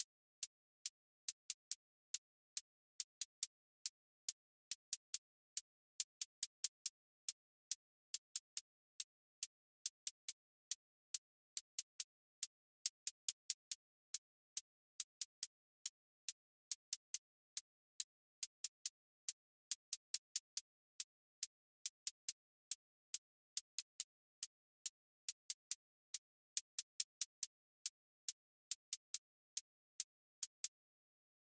QA Test — trap hats a
Trap 808 tension with clipped hats